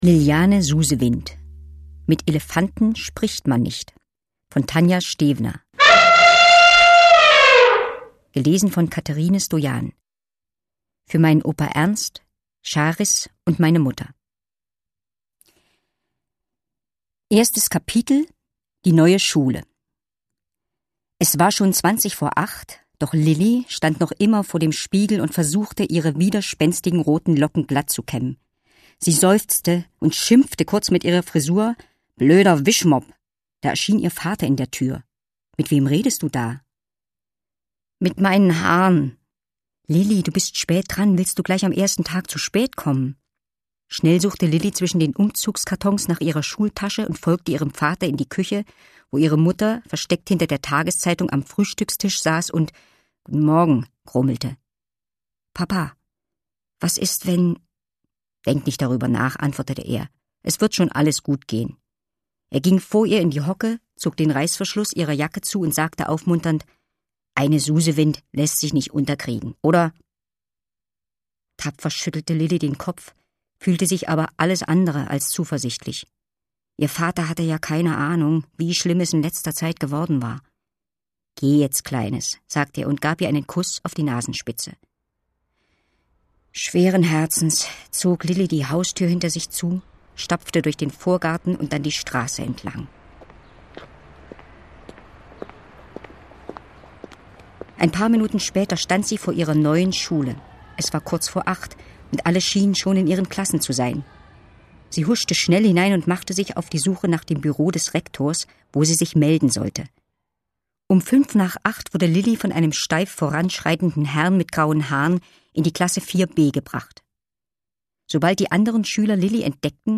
Facettenreich erschafft sie die schönsten Hörerlebnisse und gibt allen Tieren eine hinreißende Stimme.
Sie ist ein wahres Stimmwunder und verleiht allen Tieren ganz eigene Persönlichkeiten.
Schlagworte Abenteuer • Bonsai • Doktor Dolittle • Elefant • Erstleser • Fantasy • Ferien • Frau von Schmidt • Freundschaft • Freundschaft und Liebe • Hörbuch; Lesung für Kinder/Jugendliche • Jesahja • Kinderbuch • Kinder/Jugendliche: Action- & Abenteuergeschichten • Kinder/Jugendliche: Action- & Abenteuergeschichten • Kinder/Jugendliche: Natur- & Tiergeschichten • Kinder/Jugendliche: Natur- & Tiergeschichten • Liebe • Lilli • mit Tieren sprechen • Mobbing • Mut • Neue Schule • Pflanzen • Phantasie • Phantasie und Fantasy • Schule und Ferien • Tierdolmetscherin • Tier-Dolmetscherin • Tiere • Tiere; Kinder-/Jugendliteratur • Tierkommunikation • Tierschutz • Umzug • Zivilcourage